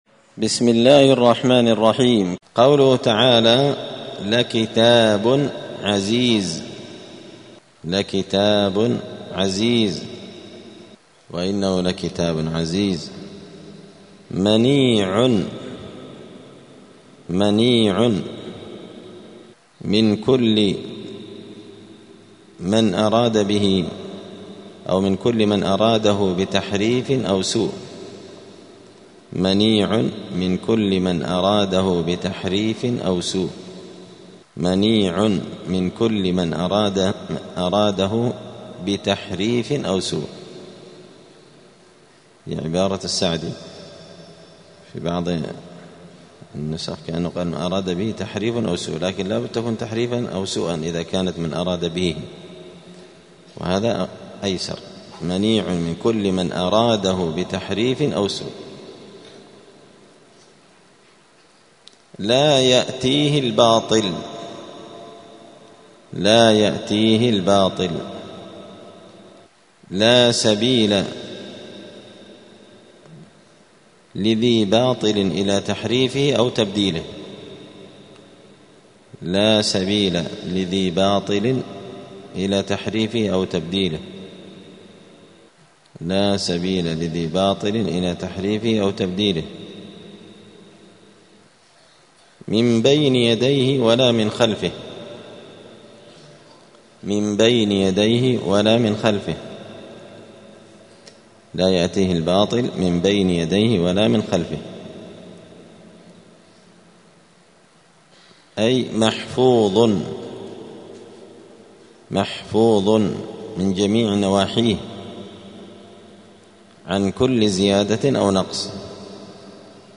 *(جزء فصلت سورة فصلت الدرس 221)*